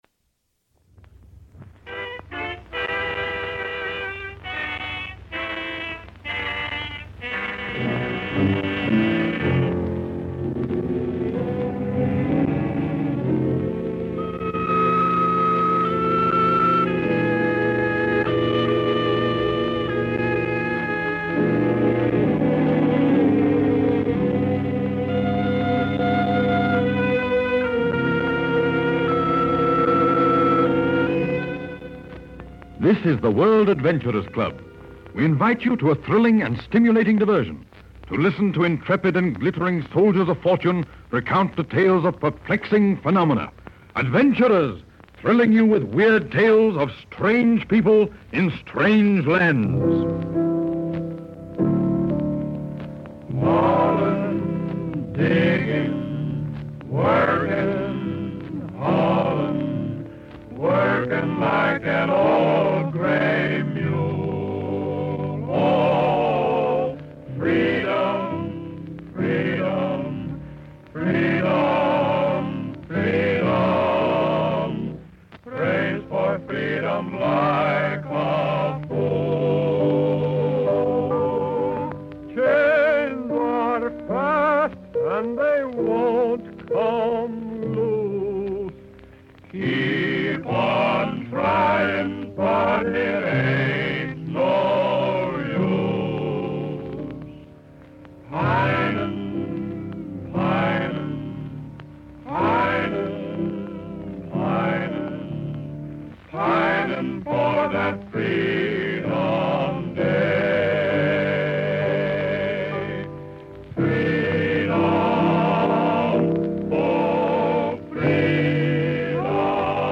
The episode, a gem from the golden age of radio, offers a glimpse into exotic locales and extraordinary events, all from the comfort of your armchai